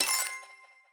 Prize Wheel Spin 1 Reward.wav